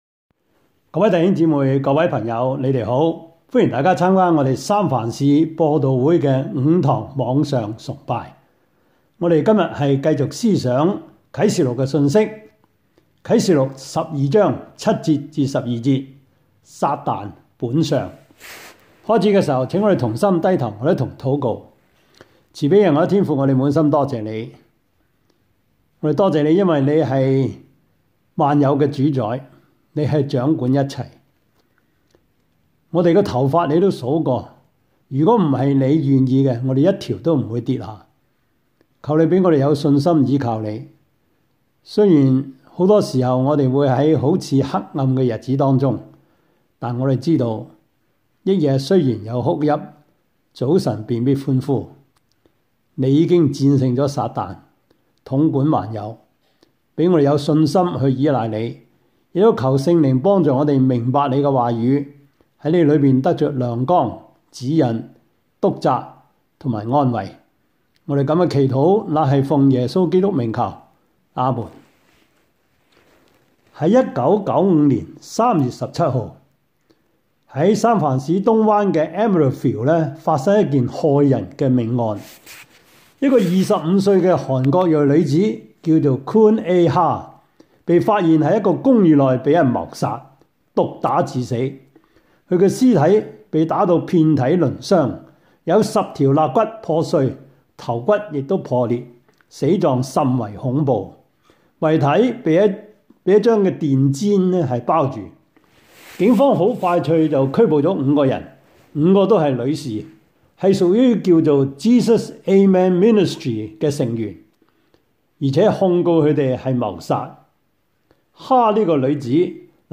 Service Type: 主日崇拜
Topics: 主日證道 « 本地薑唔辣 第三十課:英國的宗教改革 1 »